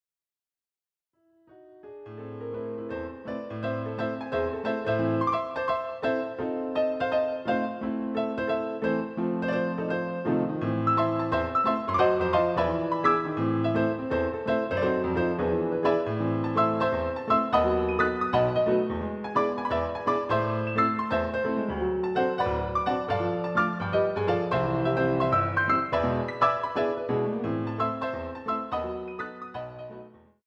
using the stereo sampled sound of a Yamaha Grand Piano